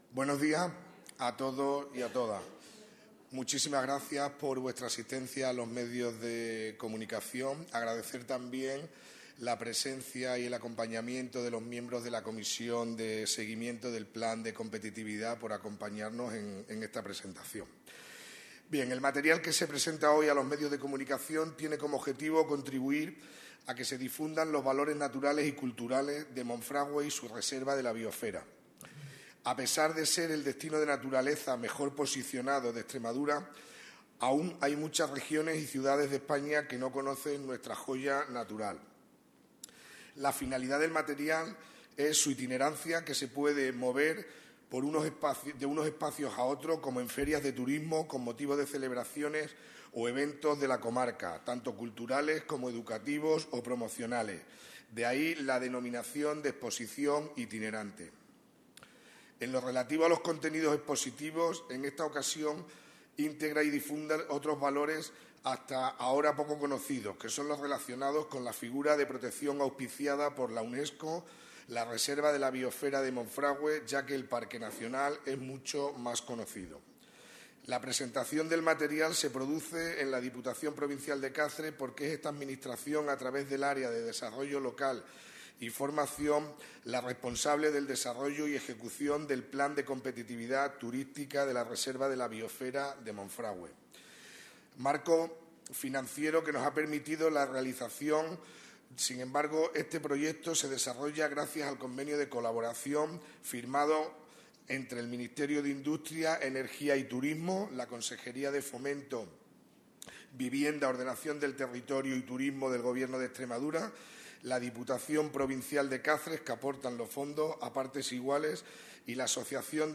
CORTES DE VOZ
19/02/2014-. El diputado de Desarrollo Local y Formación, Samuel Fernández Macarro, ha presentado este miércoles en rueda de prensa la exposición itinerante ‘La Reserva de la Biosfera de Monfragüe: sus pueblos y sus sendas’.